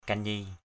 /ka-ɲi:/ (d.) đàn cò ke, đàn nhị = vièle à 2 cordes.